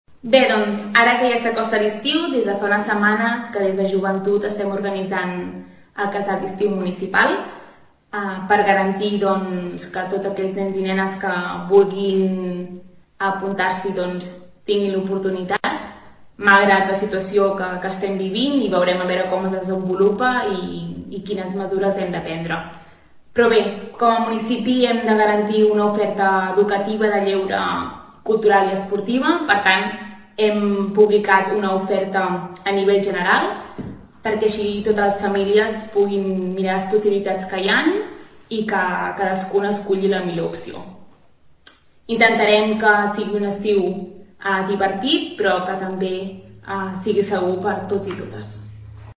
La regidora de Joventut, Bàrbara Vergés destaca que el Casal vetllarà per complir la normativa de prevenció de la COVID19.